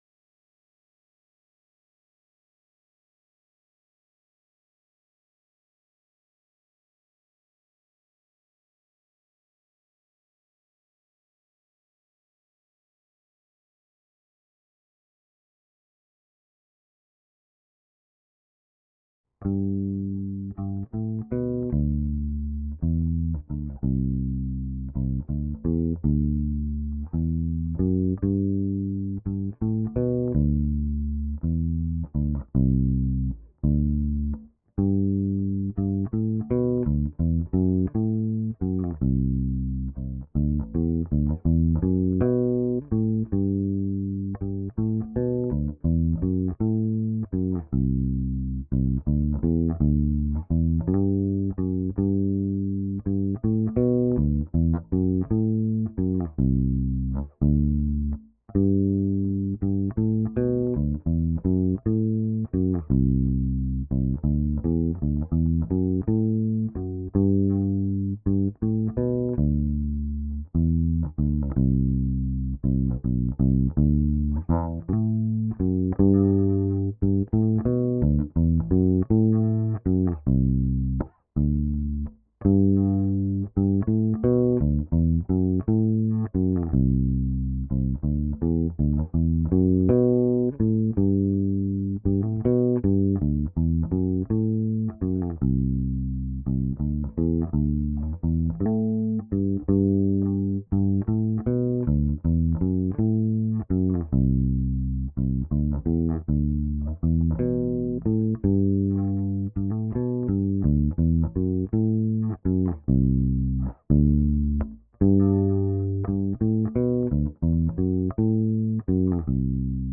bassp.wav